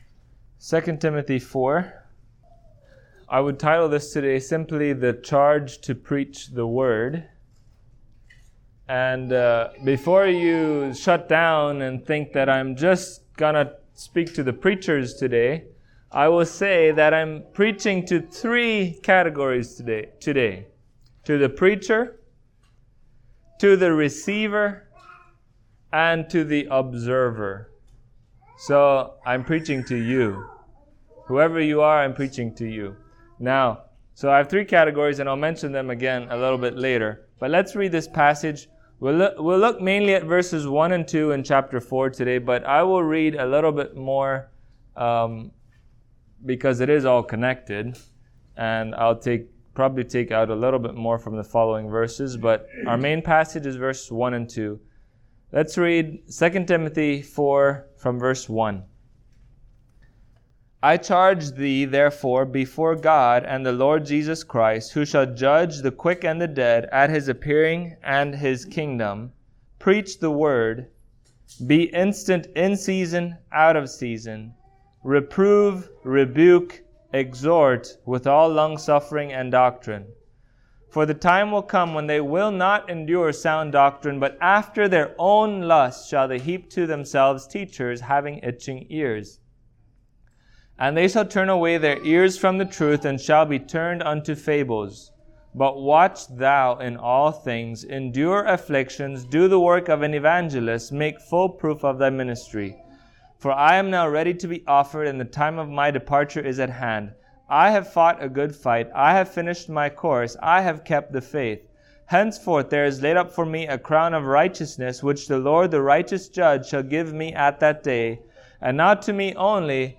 Passage: 2 Timothy 4:1-2 Service Type: Sunday Morning